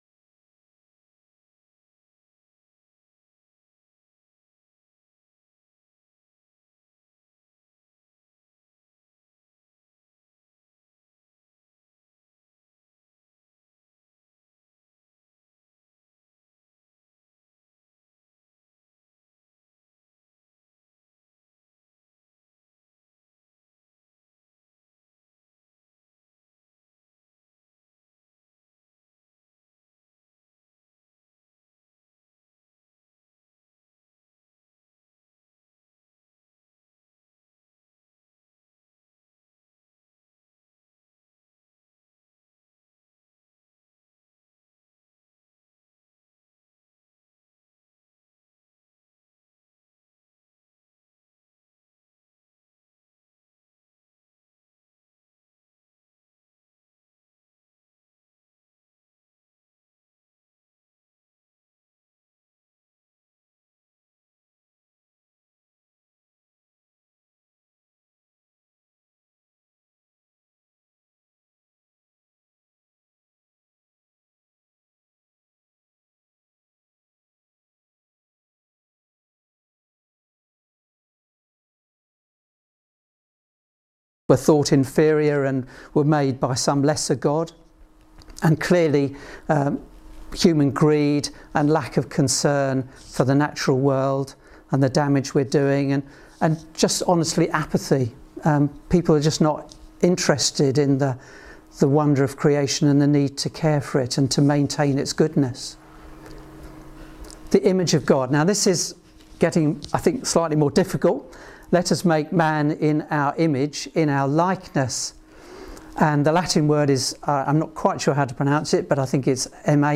Show or hear video or audio of talks or sermons from the Thornhill Baptist Church archives.